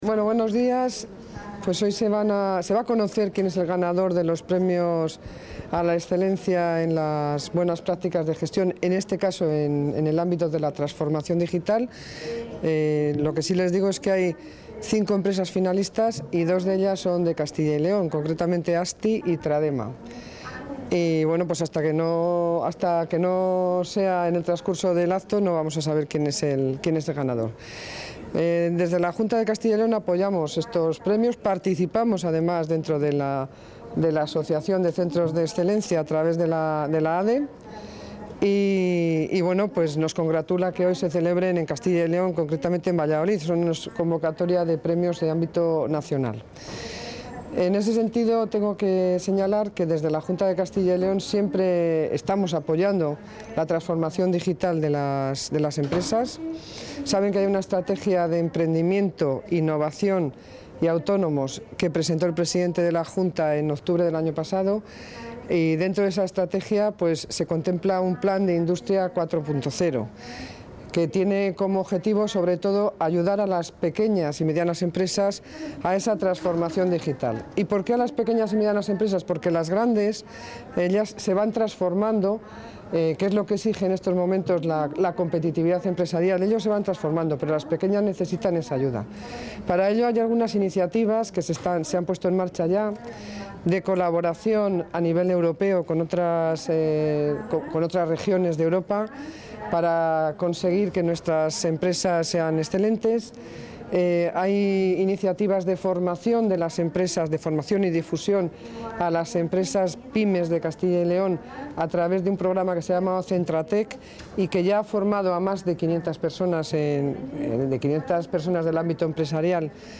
Declaraciones de la consejera de Economía y Hacienda.
La consejera de Economía y Hacienda, Pilar del Olmo, ha entregado hoy en el edificio de la Junta de Arroyo de la Encomienda (Valladolid) el VII Premio CEX a las buenas prácticas en transformación digital, que ha recaído en la compañía Asti Technologies Group.